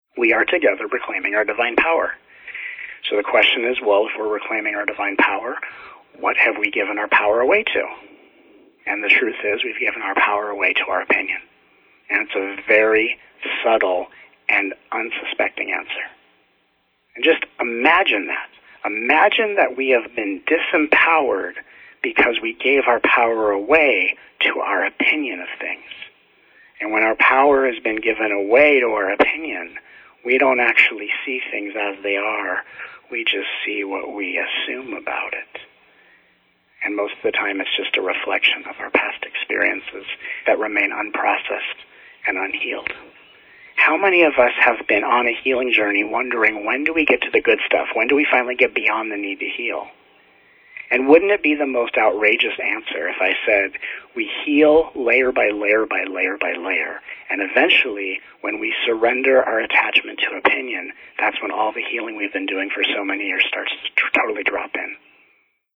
As always, there will be time in each call for questions, along with two calls specifically dedicated to Q&A and answering your biggest life questions.